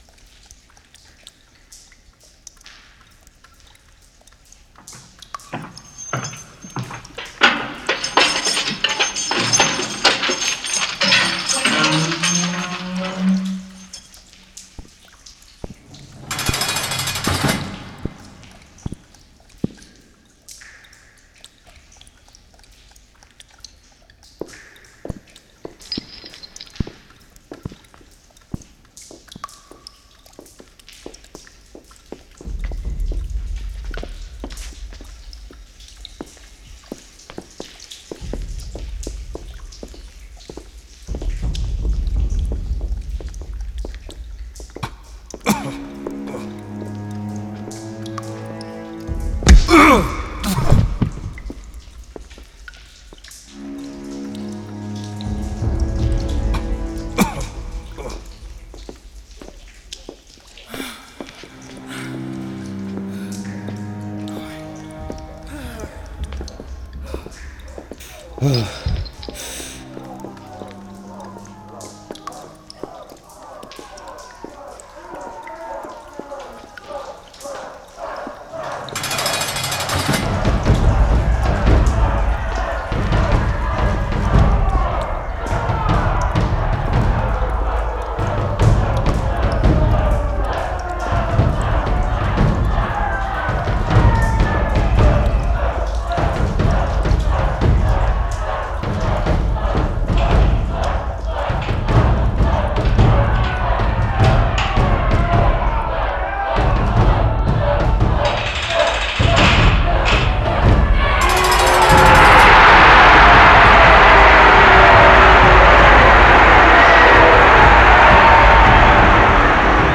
The source were several actors chanting live, including the gladiator herself as a fun reversal of roles. Gravel sounds were from the playground nearby.